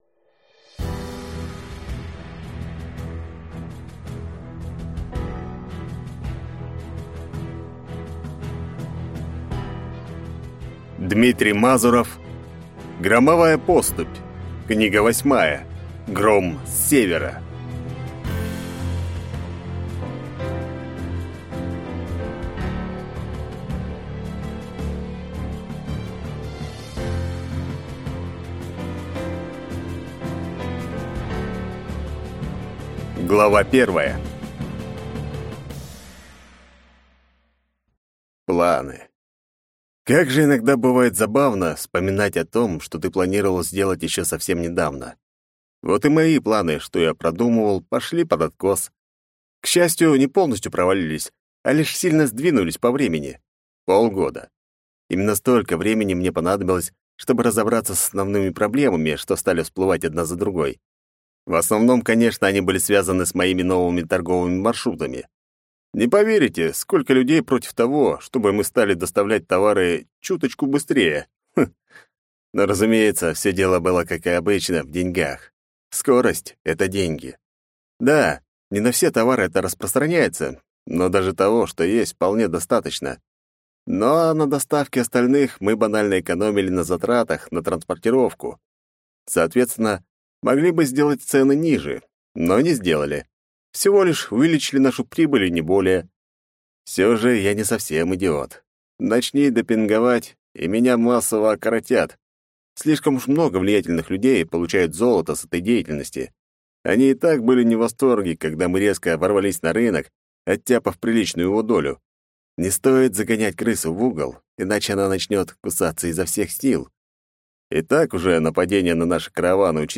Аудиокнига Громовая поступь 8. Гром с севера | Библиотека аудиокниг